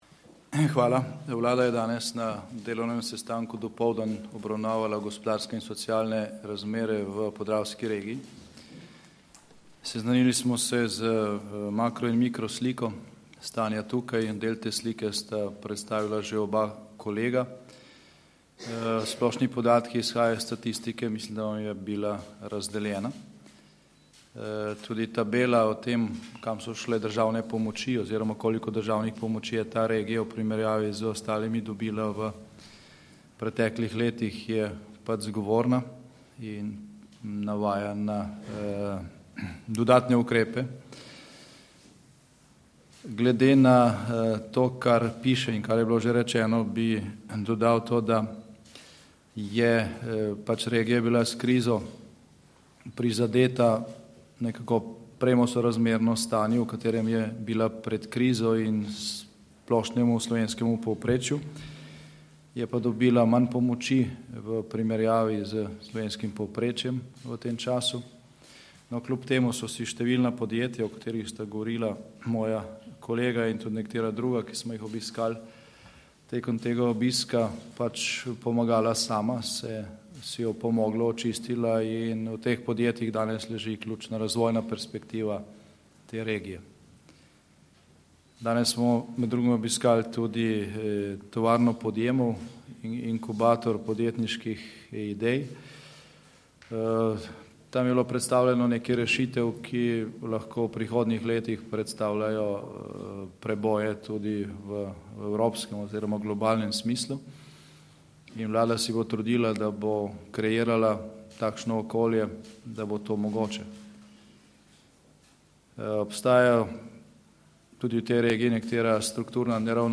Z dopoldanskim delovnim posvetom vlade v Mariboru, je premier Janez Janša z ministrsko ekipo danes nadaljeval obisk Podravske regije. Kot je dejal na popoldanski novinarski konferenci, je osnovna ugotovitev ob obisku v Podravju v tem, da je bila regija s krizo prizadeta in da je v tem času dobila manj pomoči v primerjavi s slovenskim povprečjem.